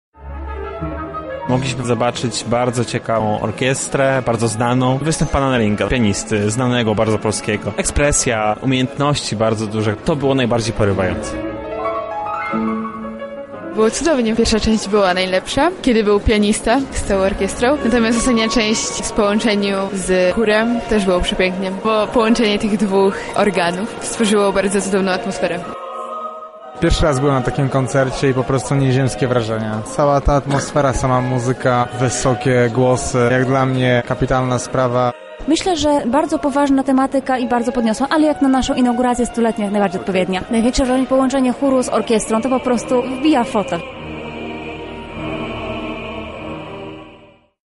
Utwory polskich kompozytorów zagrane na jubileuszowym koncercie z okazji 100-lecia KUL